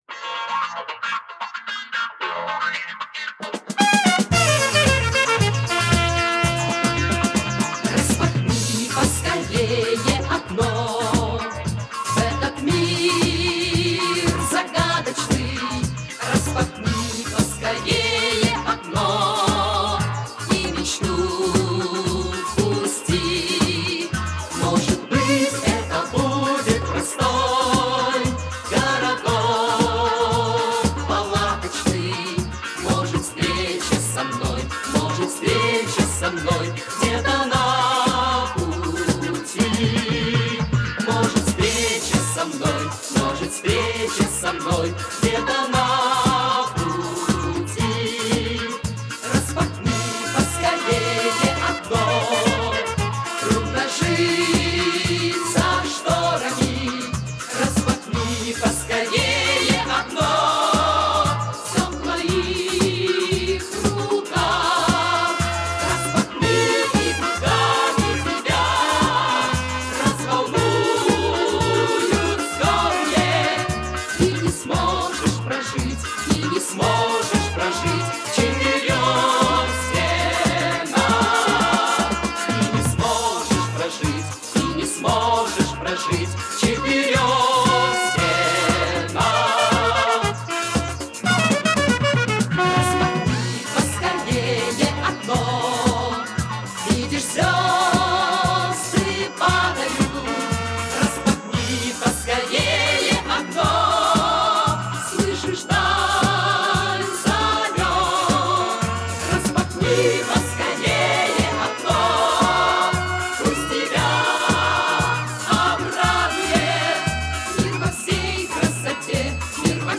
Жанр: Rock, Pop
Стиль: Vocal, Pop Rock, Schlager